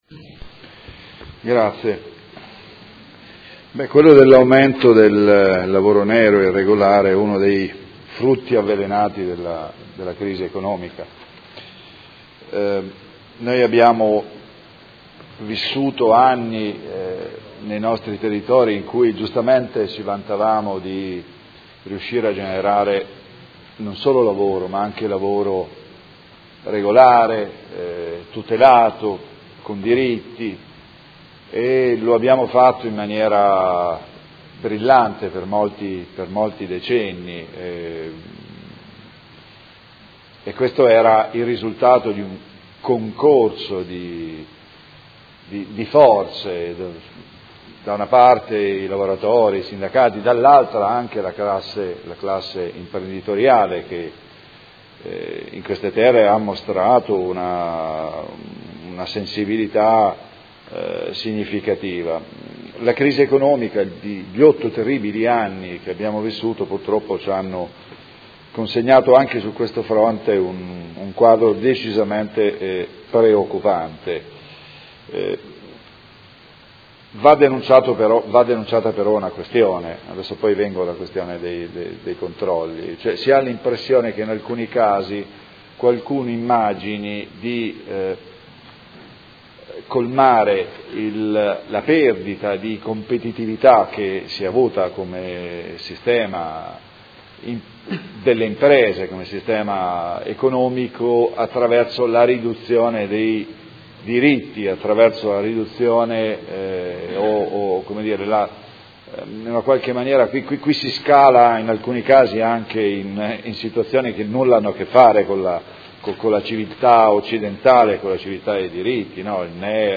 Seduta del 15/06/2017. Dibattito su Ordine del Giorno presentato dal Gruppo Art.1-MDP avente per oggetto: Affrontare con urgenza i gravi problemi di irregolarità del lavoro che emergono dai controlli ispettivi ed Emendamenti